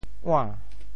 “碗”字用潮州话怎么说？
ua~2.mp3